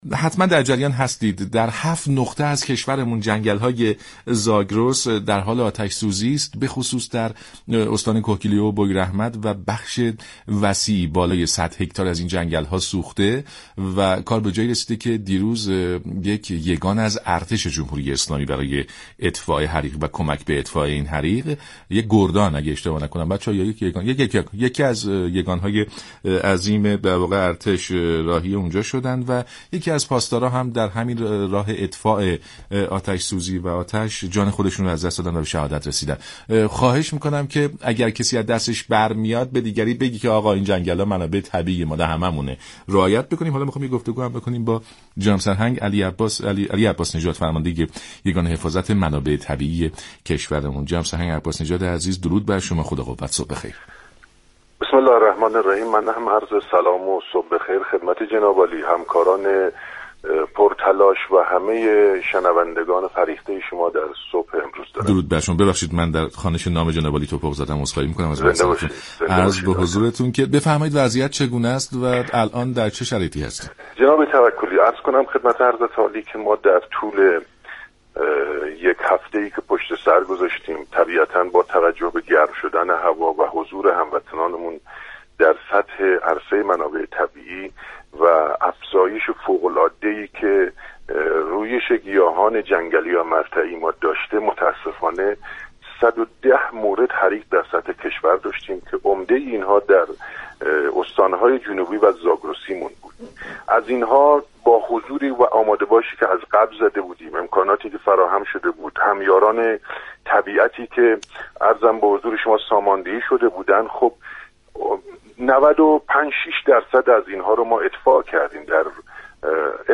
فرمانده یگان حفاظت منابع طبیعی كشور در برنامه «سلام صبح بخیر» گفت: استان كهگیلویه و بویر احمد و مناطق جنوبی كشور به دلیل صخره ای بودن، امكان كمك زمینی ندارند و متاسفانه كمك هوایی نیز در وقت مقرر انجام نشد.